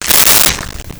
Touch Fastener Rip
Touch Fastener Rip.wav